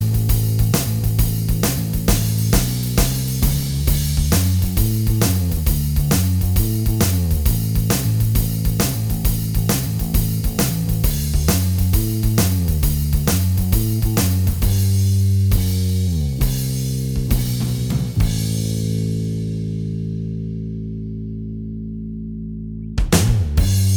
Minus Guitars Rock 3:45 Buy £1.50